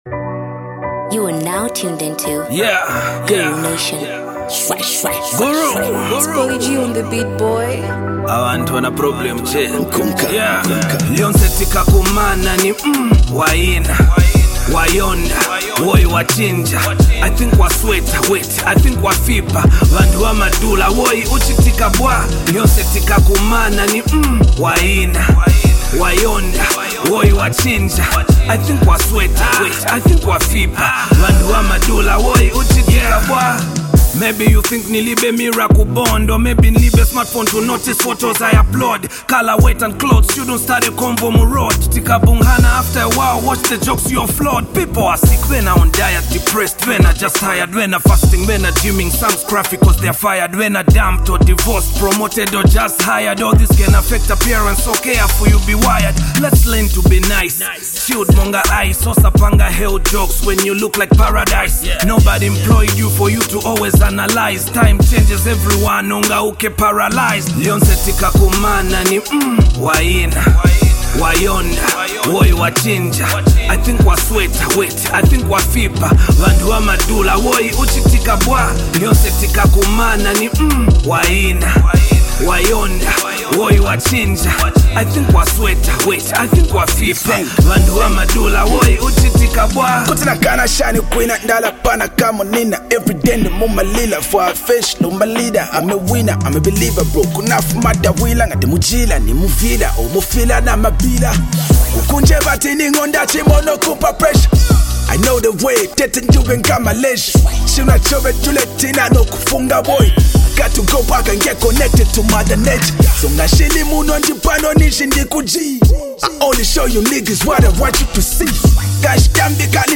fellow rapper